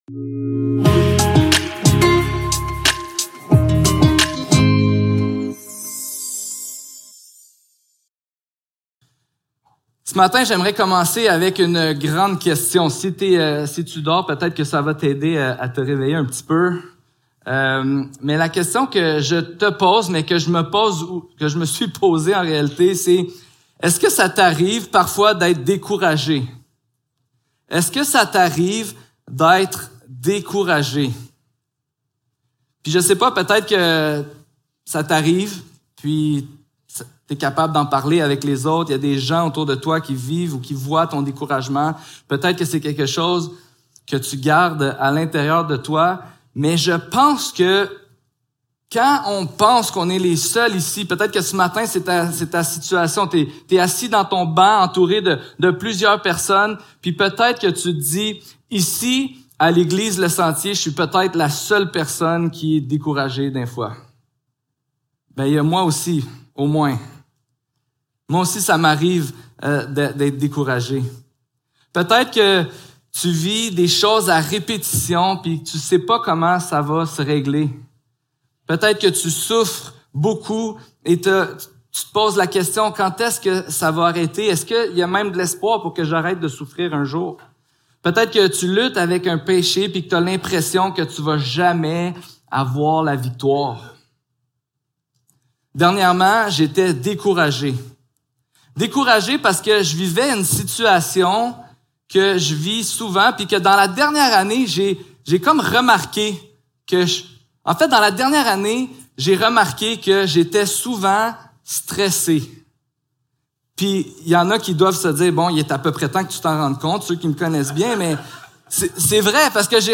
1 Jean 1.5 à 2. 2 Service Type: Célébration dimanche matin Description